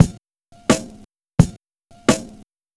Track 02 - Kick Snare Beat 01.wav